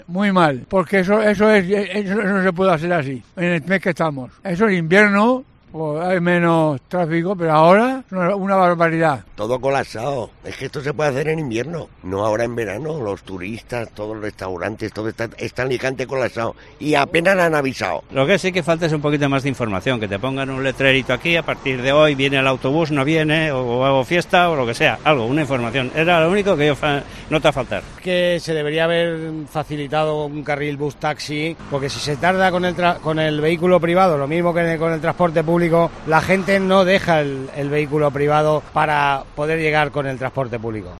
Opinión de los ciudadanos sobre las obras del centro de Alicante